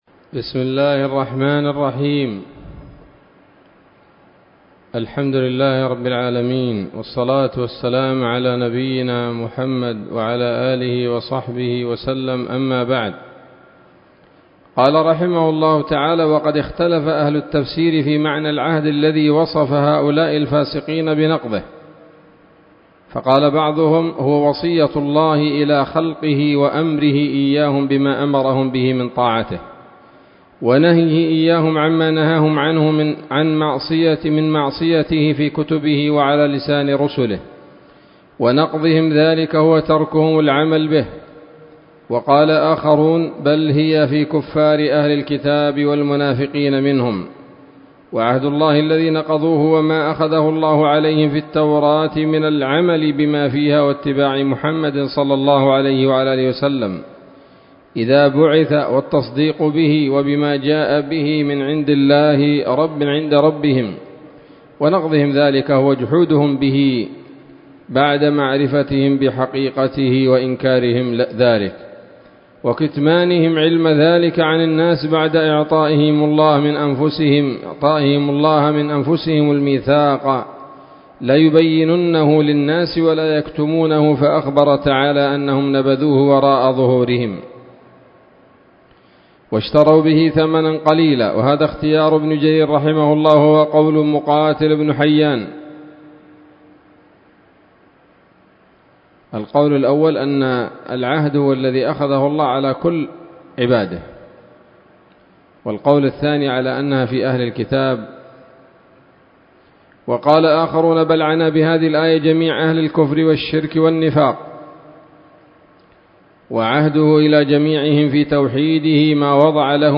الدرس الثلاثون من سورة البقرة من تفسير ابن كثير رحمه الله تعالى